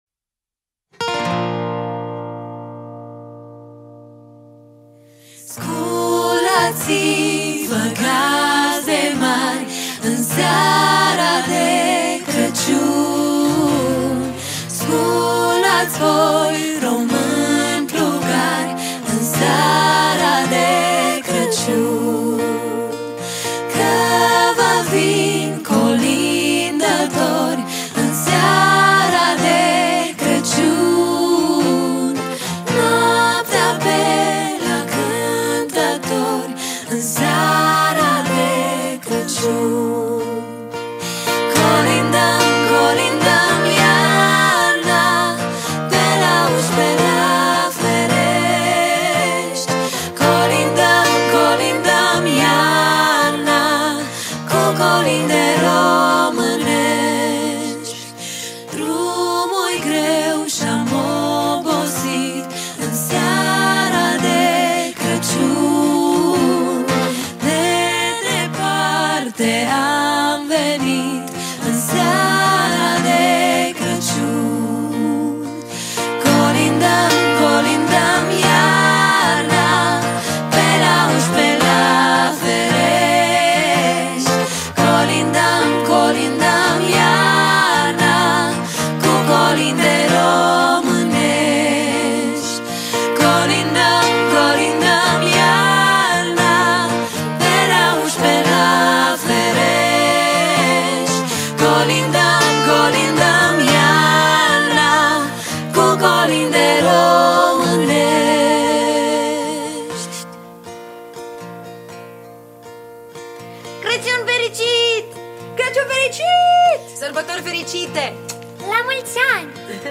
Data: 12.10.2024  Colinde Craciun Hits: 0